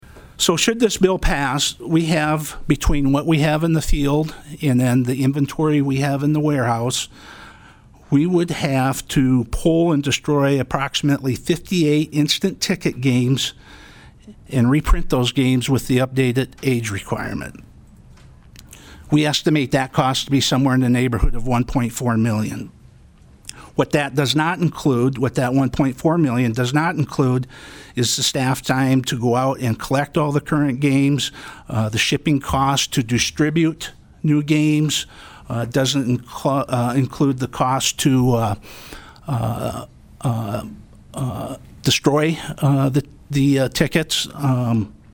PIERRE, S.D.(HubCityRadio)- The South Dakota Senate Commerce & Energy Committee heard testimony on SB203 on Thursday.